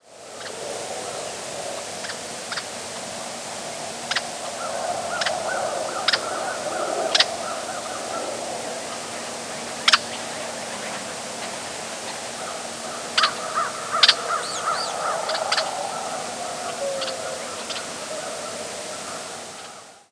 Red-winged Blackbird diurnal flight calls
Bird in flight giving "ki-drk" call with American Crow and American Robin in the background.